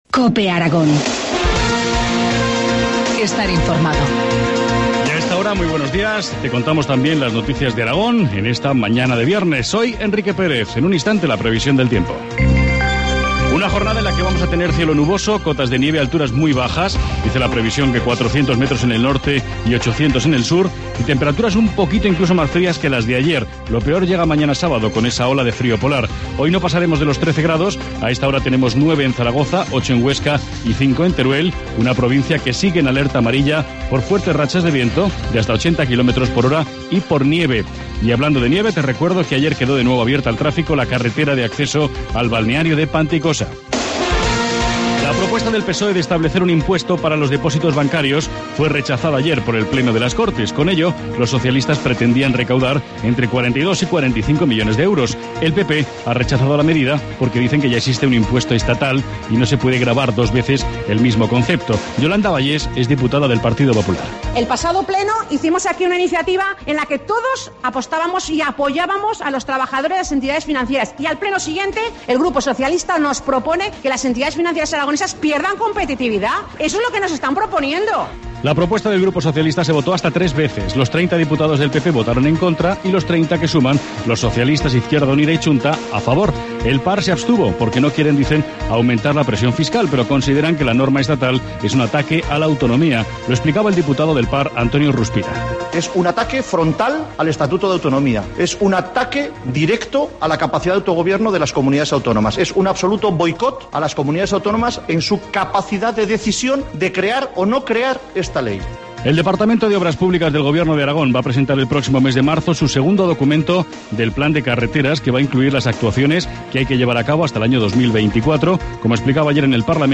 Informativo matinal, viernes 22 de febrero, 8.25 horas